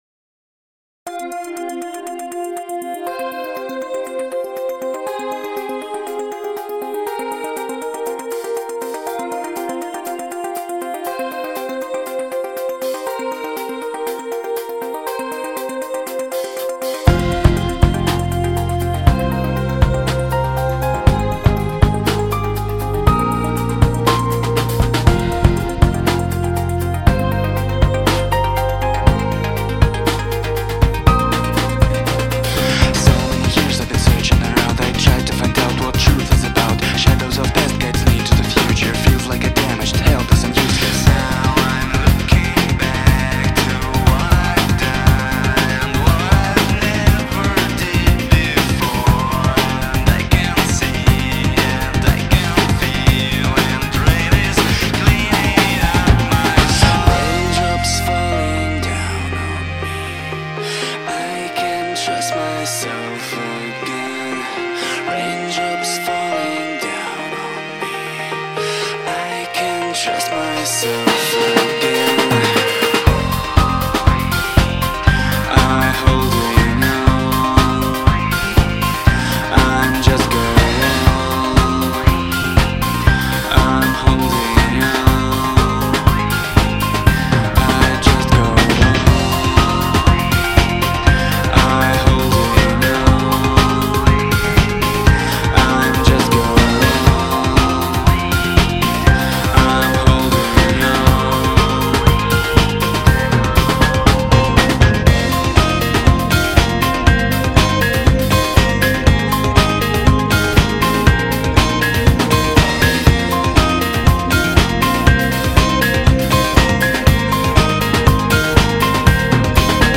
synth, indie, rock